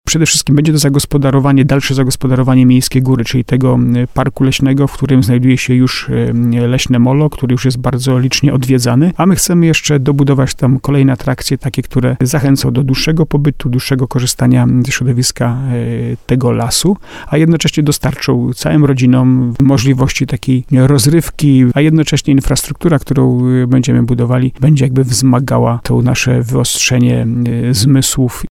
zapowiada Jacek Lelek, burmistrz Starego Sącza.